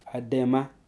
This is a tenative list of words elicited in Shua.
Some recordings are suboptimal and there are errors needing to be corrected. Tone marks are approximate, and the levels indicated here are from highest to lowest s,h,m,l,x. Nasalization is marked with N at the end of the syllable; root-medially, /b/ is pronounced as [β].